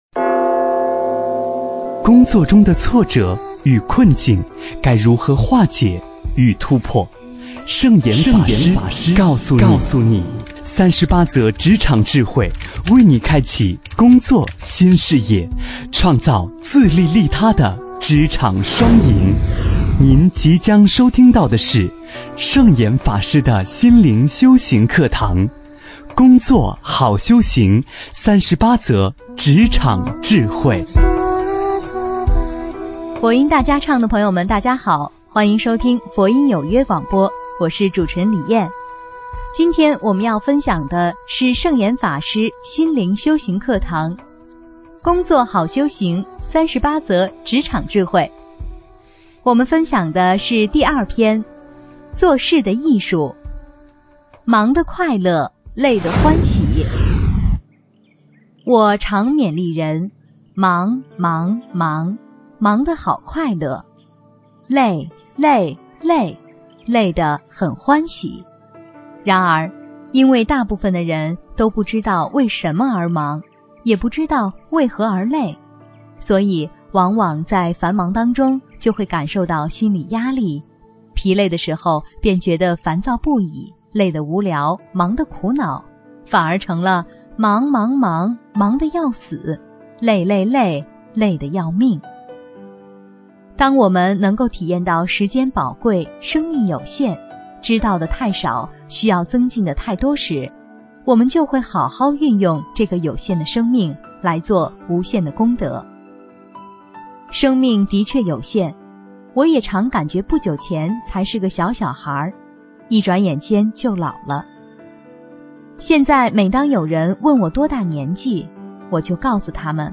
职场10忙的快乐累的欢喜--佛音大家唱 真言 职场10忙的快乐累的欢喜--佛音大家唱 点我： 标签: 佛音 真言 佛教音乐 返回列表 上一篇： 职场07工作不只是保住饭碗--佛音大家唱 下一篇： 职场13工作与心安--佛音大家唱 相关文章 2.成就：波罗蜜--释星云 2.成就：波罗蜜--释星云...